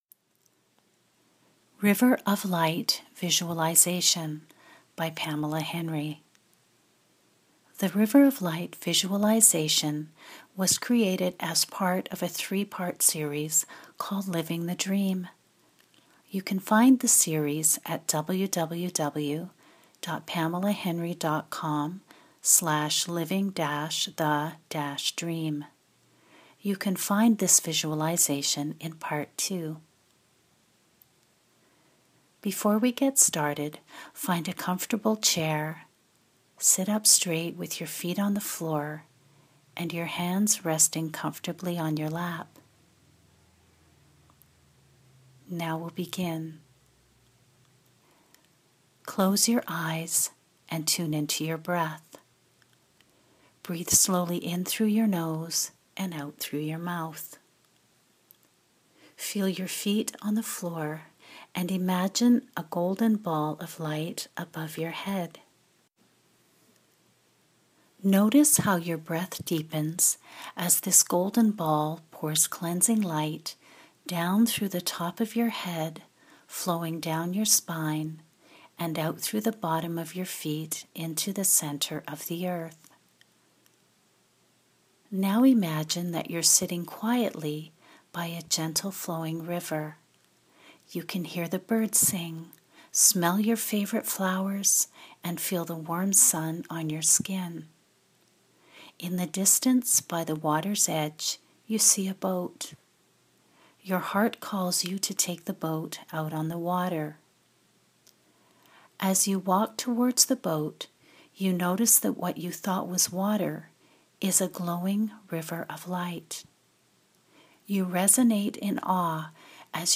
River of Light Visualization audio